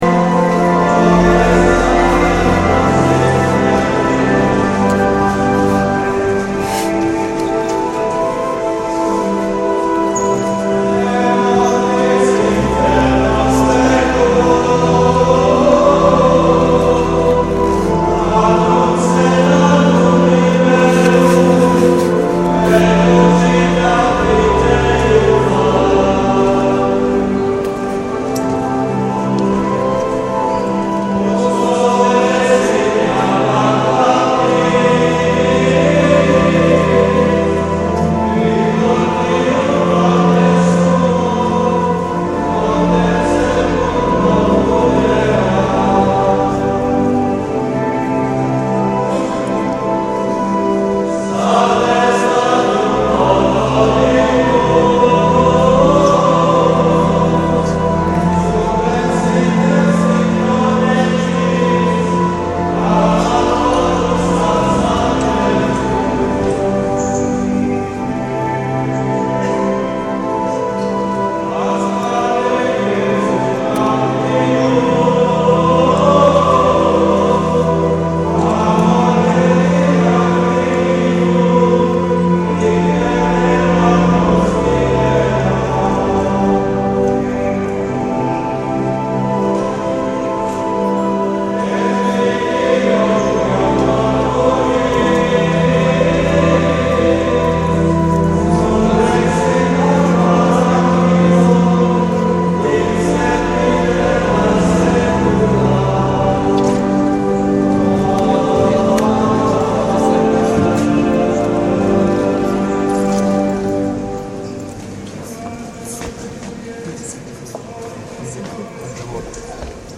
Modlitwa - śpiew 6